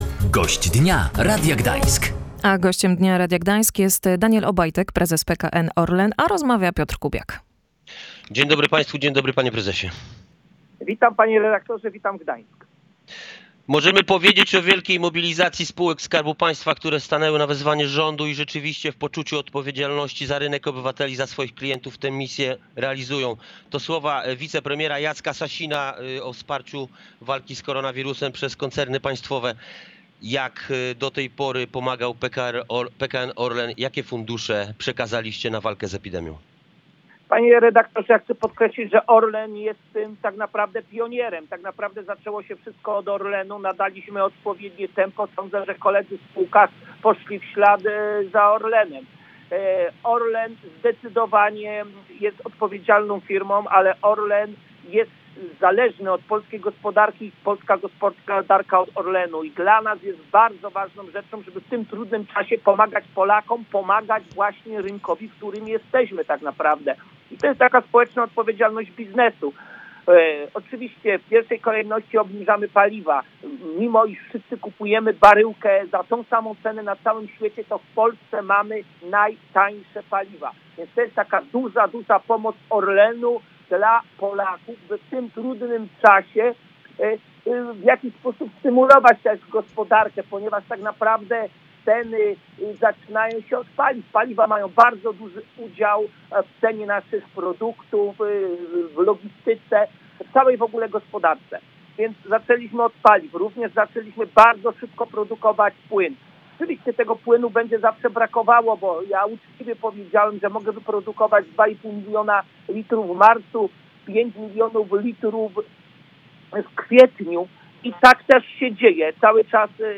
O tym w rozmowie